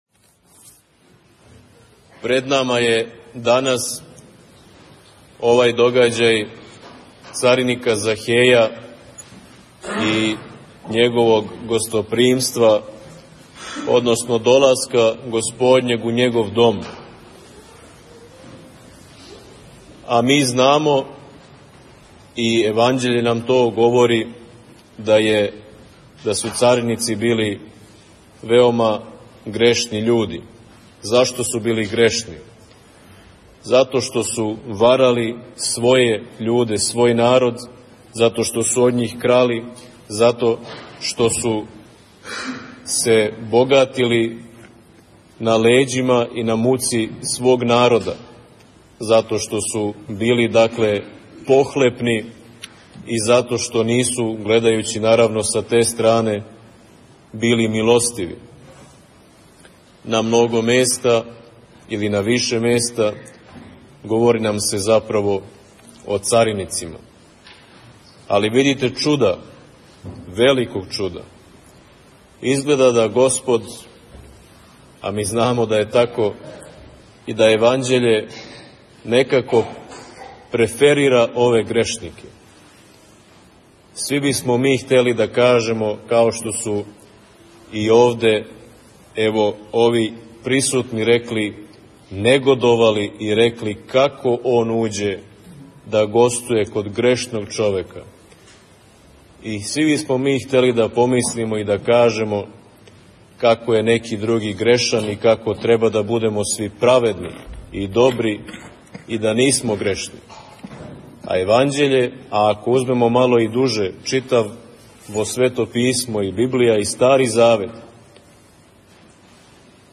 Беседу преносимо у целости у аудио формату.